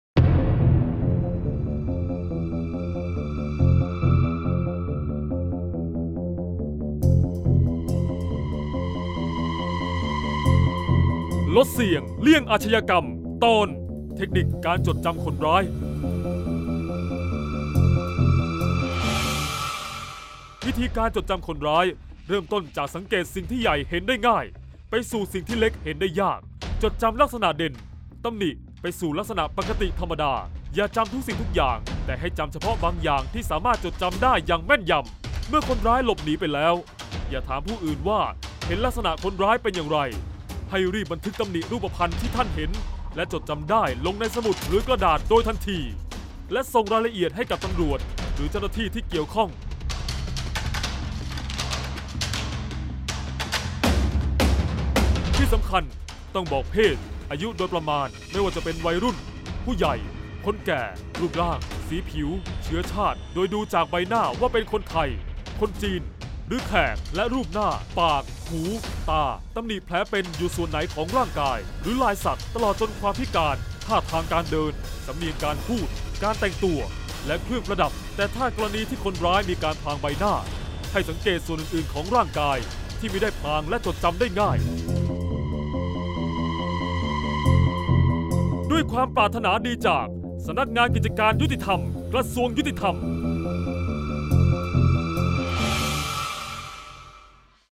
เสียงบรรยาย ลดเสี่ยงเลี่ยงอาชญากรรม 29-เทคนิคจดจำคนร้าย